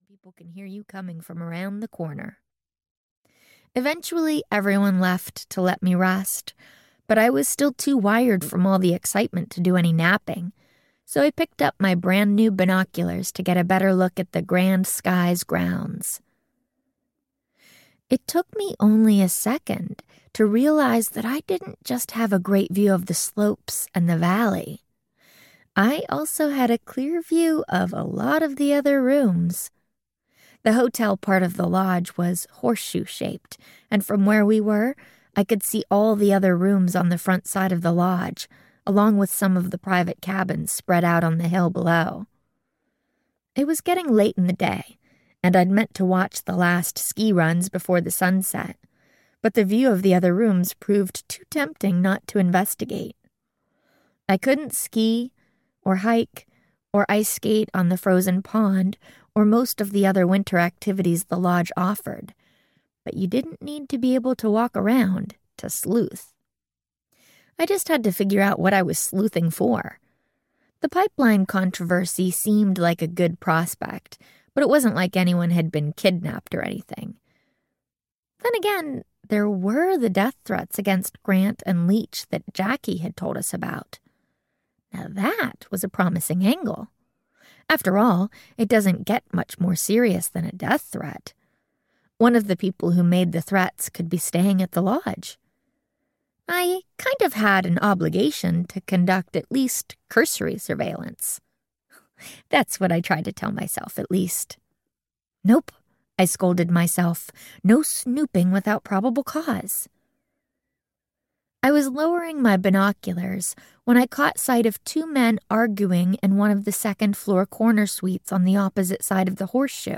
A Nancy Drew Christmas Audiobook
Narrator
6.42 Hrs. – Unabridged